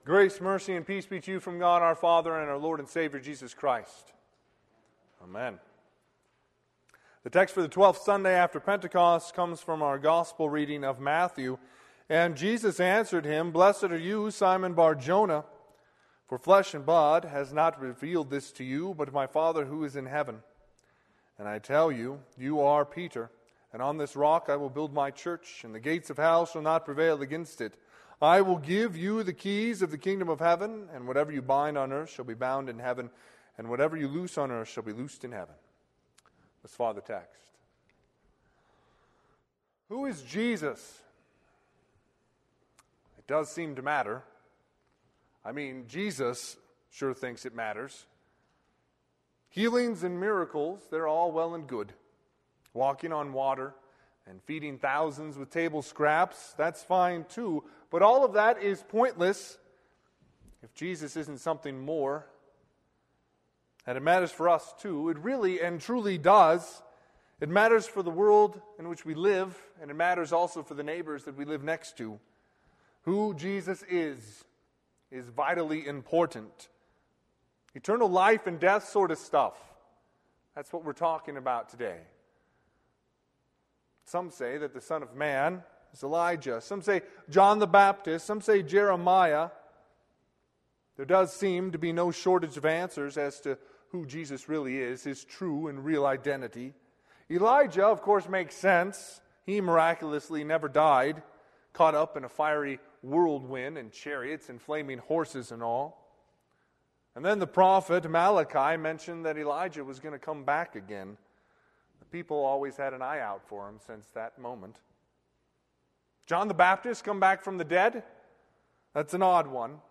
Sermon - 8/23/2020 - Wheat Ridge Lutheran Church, Wheat Ridge, Colorado